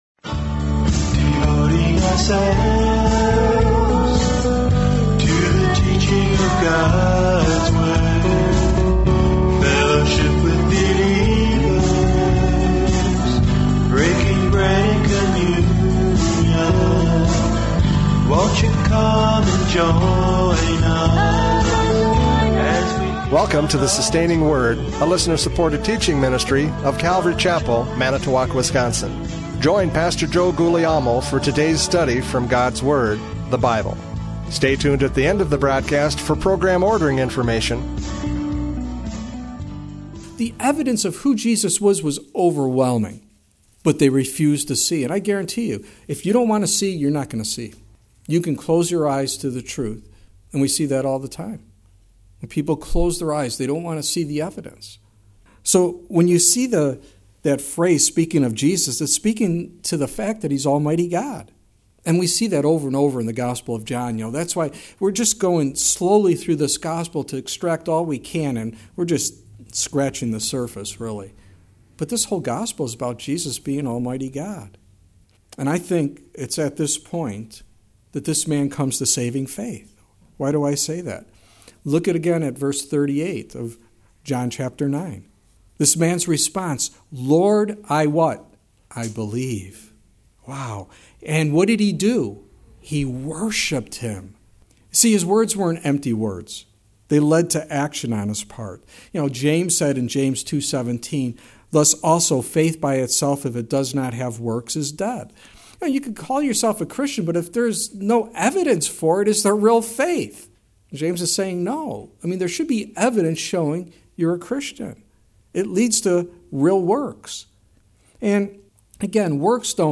John 9:35-41 Service Type: Radio Programs « John 9:35-41 Spiritual Blindness!